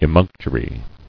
[e·munc·to·ry]